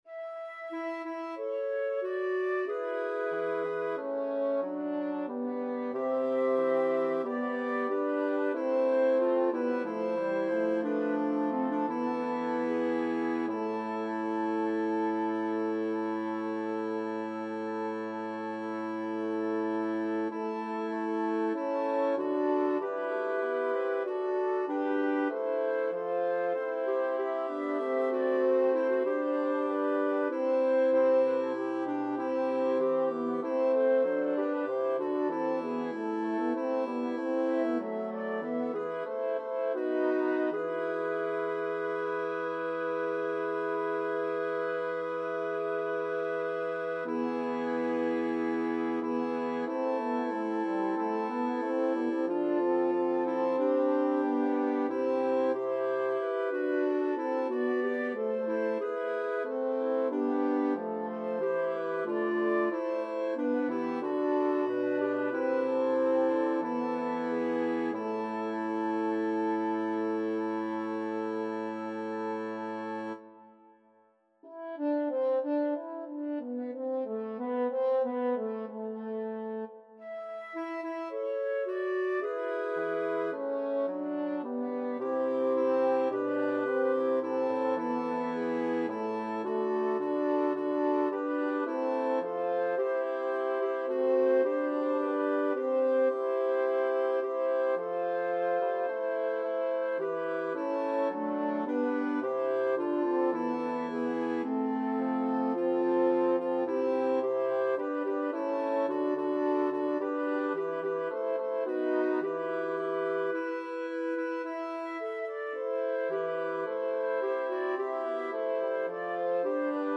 Title: Missa Avecques vous Composer: Johannes Lockenburg Lyricist: Number of voices: 4vv Voicing: SATB Genre: Sacred, Mass
Language: Latin Instruments: A cappella
Score information: A4, 28 pages, 442 kB Copyright: CPDL Edition notes: Transposed up a tone.